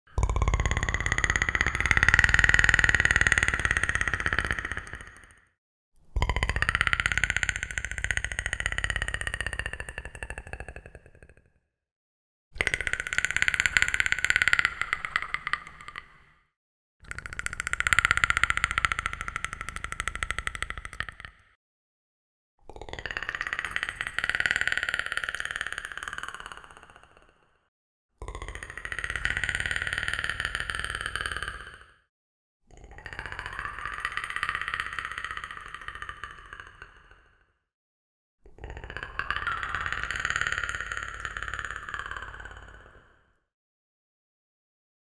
Predator-sound-effects-free.mp3